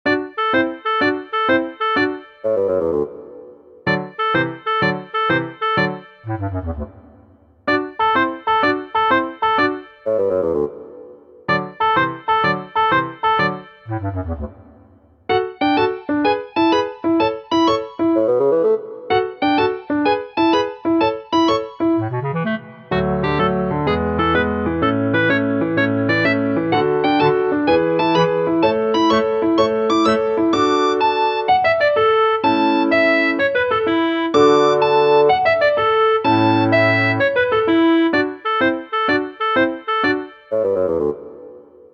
ogg(L) 日常系 楽しい 木管
ピアノに乗せて木管たちが楽しそうに。